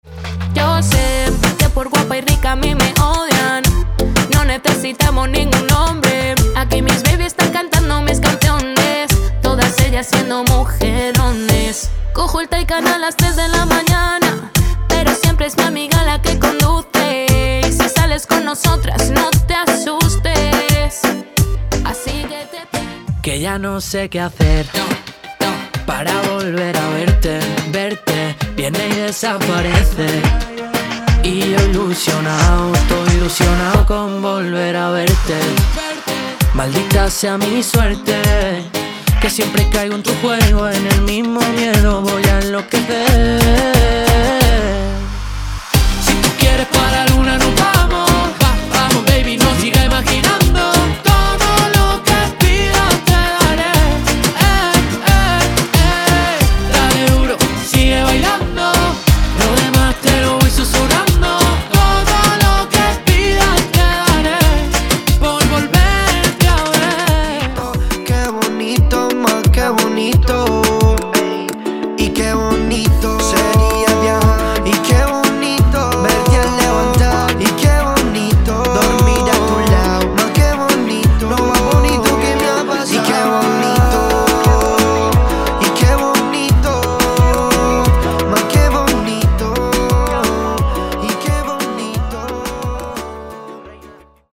Reaggeton Samples_WqtBtVGY6L.mp3